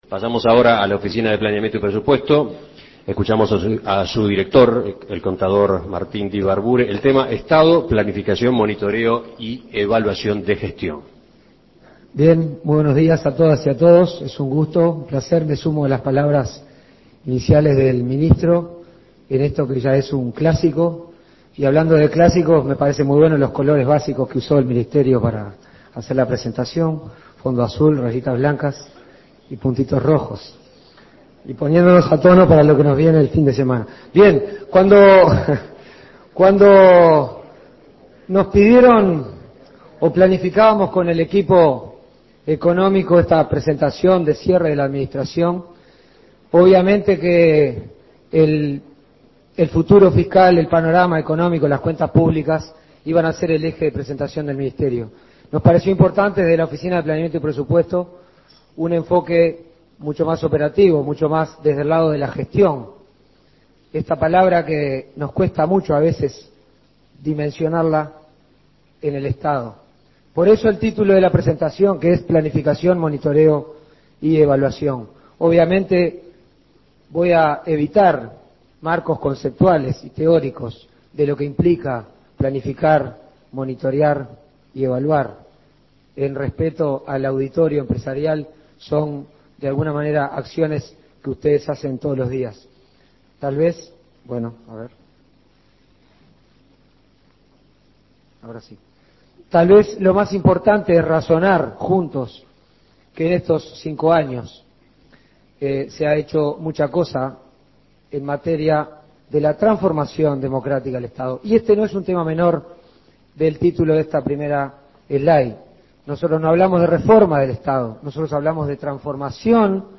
Escuche la exposición de Martín Dibarboure, director de la Oficina de Planeamiento y Presupuesto